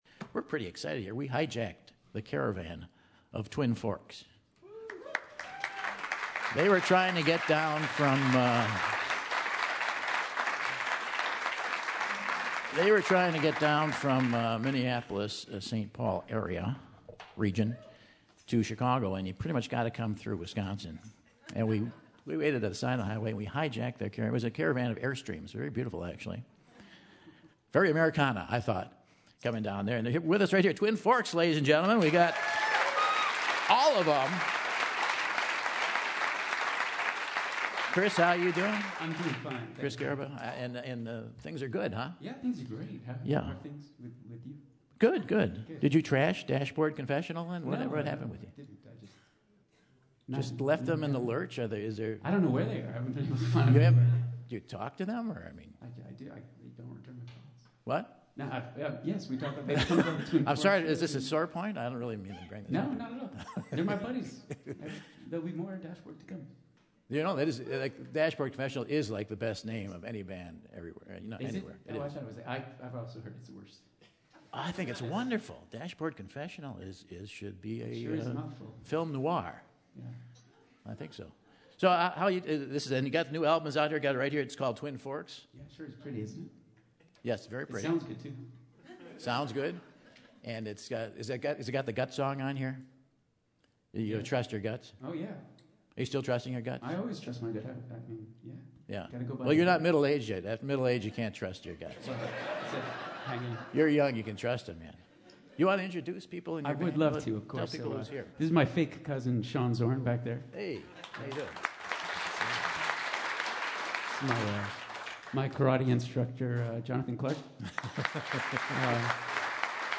Indie folk band
stop by the Terrace to play a few songs